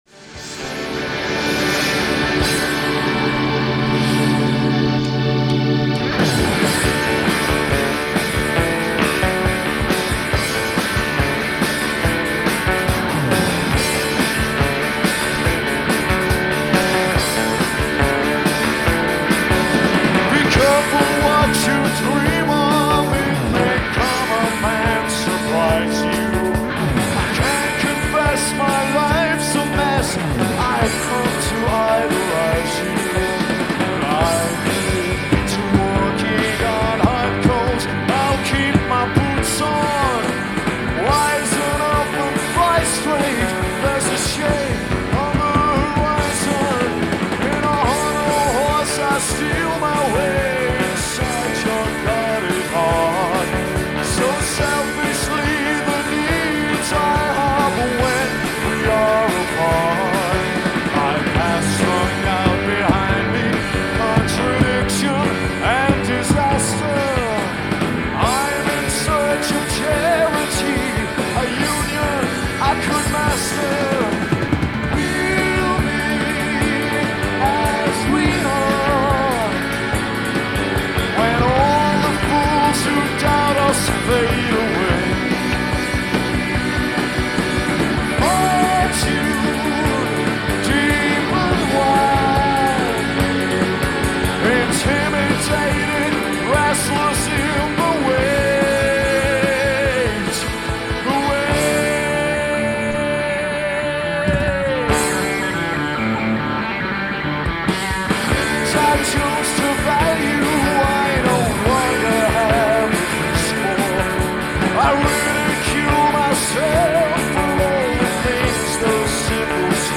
live The Watershed, Bristol
recorded at The Watershed in Bristol